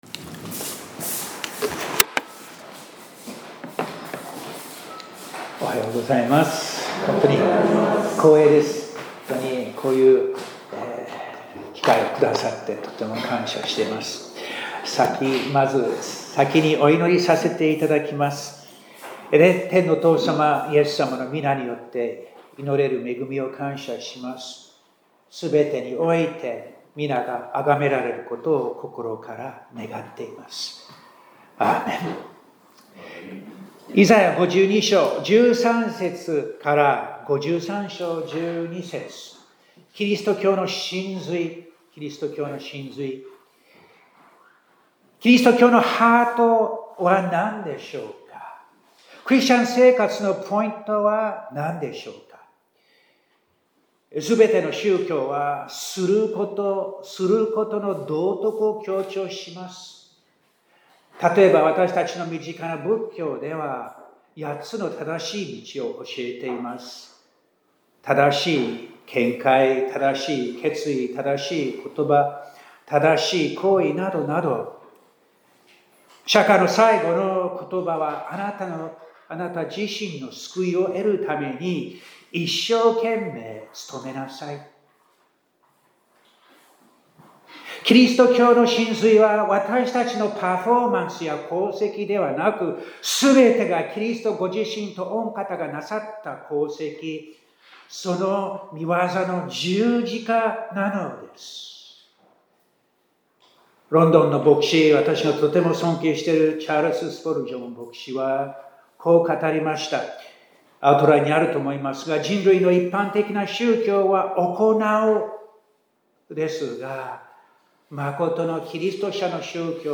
2025年10月26日（日）礼拝メッセージ | 国分寺キリスト教会
2025年10月26日（日）礼拝メッセージ - 香川県高松市のキリスト教会